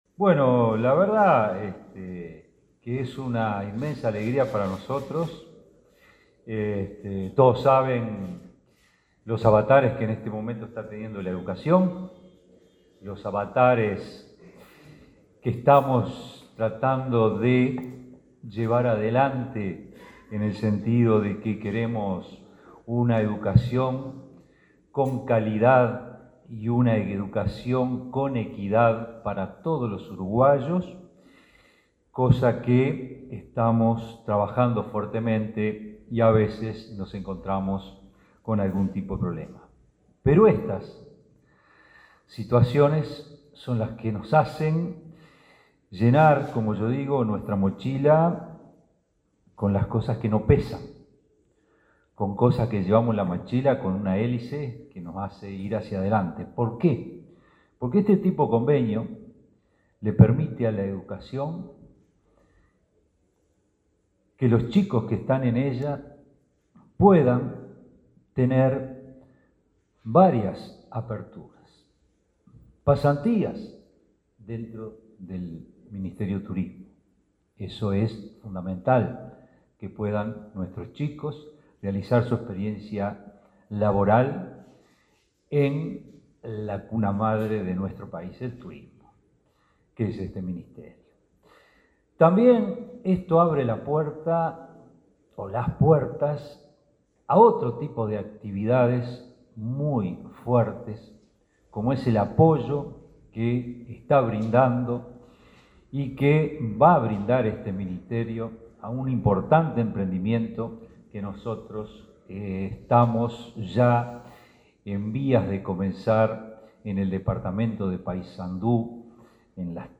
Palabras de autoridades en convenio Ministerio de Turismo-UTU